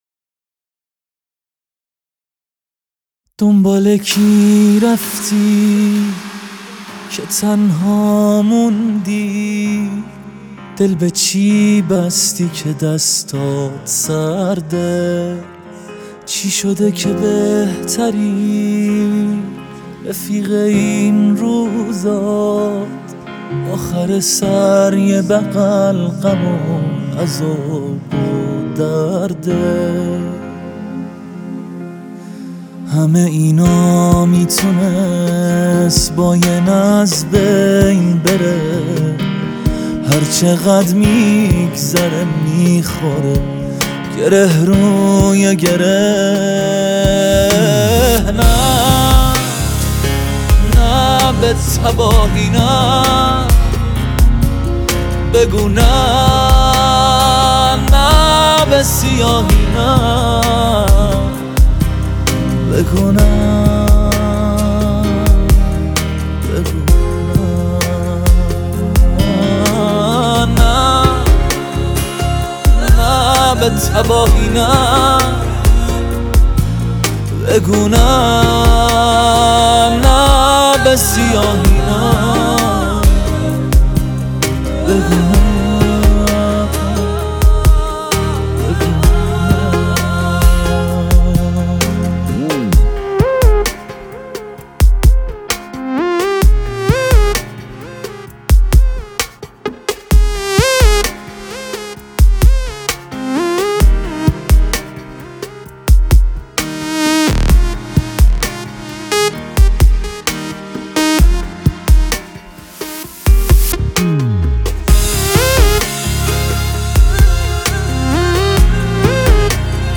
موسیقی انقلابی